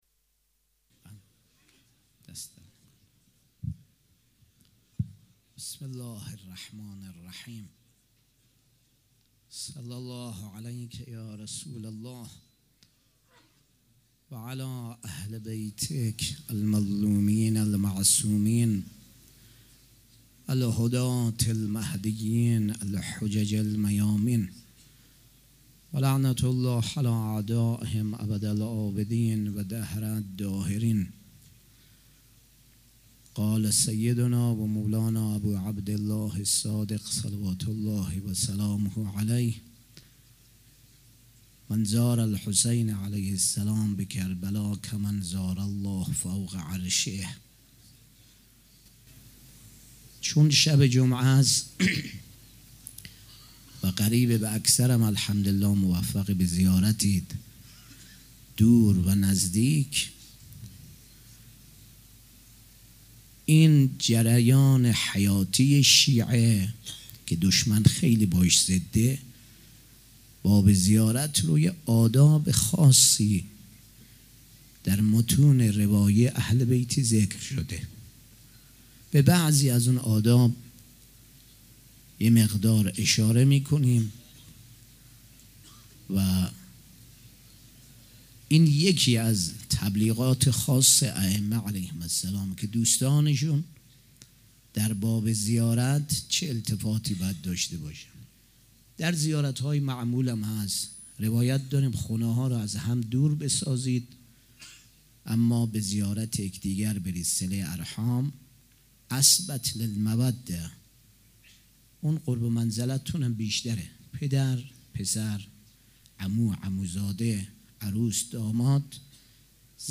سخنرانی
شب پنجم محرم الحرام‌ پنجشنبه ۱5 مهرماه ۱۳۹۵ هيئت ريحانة الحسين(س)